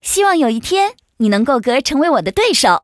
文件 文件历史 文件用途 全域文件用途 Choboong_fw_03.ogg （Ogg Vorbis声音文件，长度0.0秒，0 bps，文件大小：37 KB） 源地址:游戏语音 文件历史 点击某个日期/时间查看对应时刻的文件。